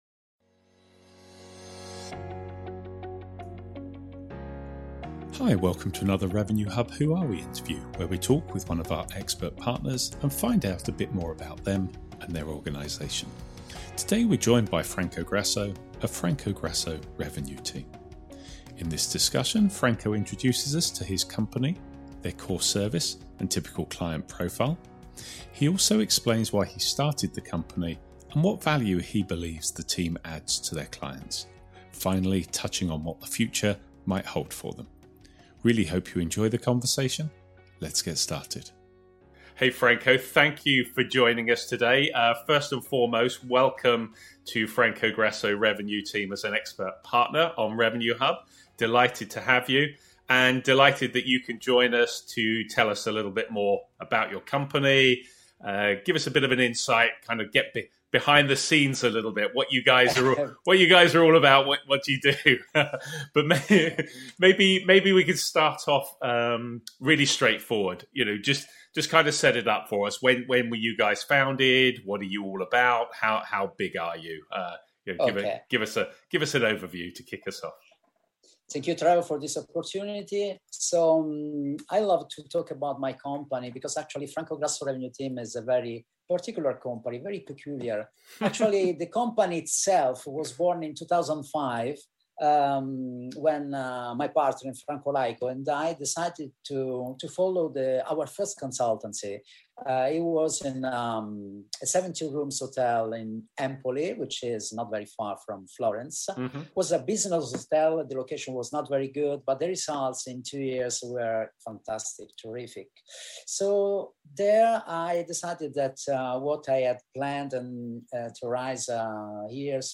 In this Who Are We interview we are joined by: